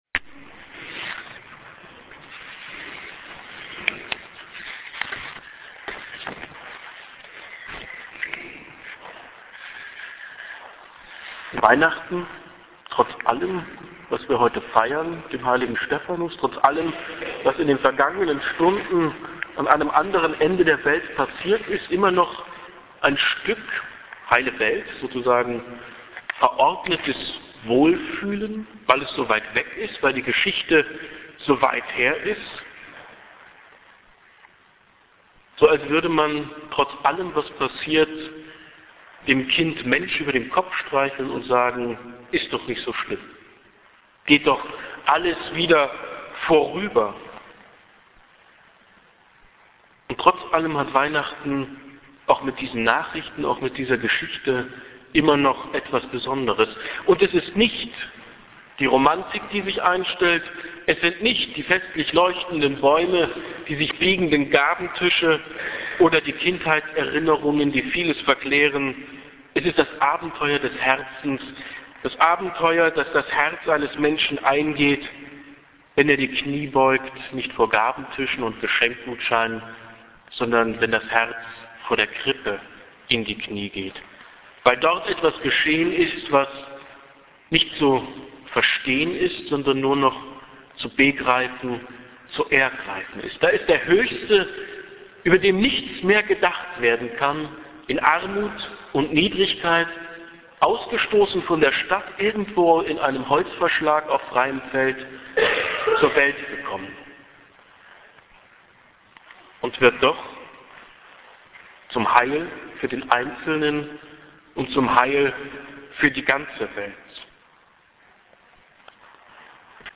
hier-klickt-die-predigt.mp3